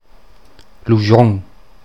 Ladinisch-mundartliche Form
[luˈʒɔŋ]
Lujon_Mundart.mp3